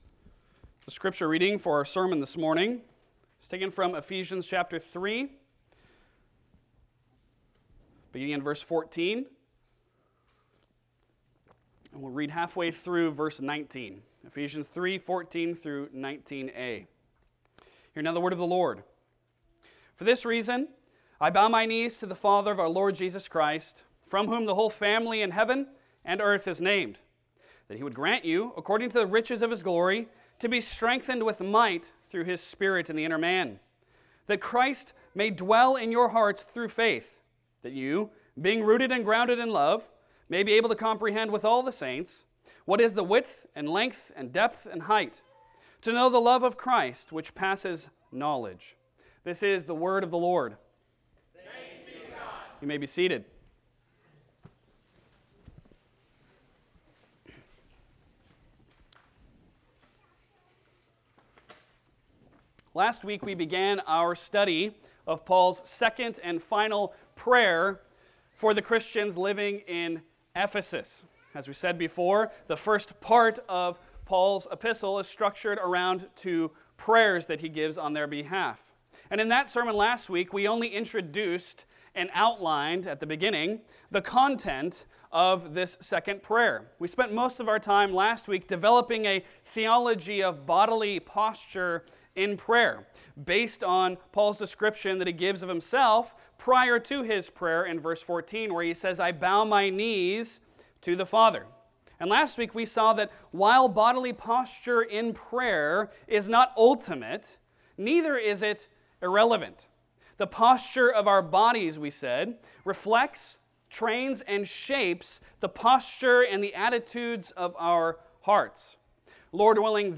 Passage: Ephesians 3:14-19a Service Type: Sunday Sermon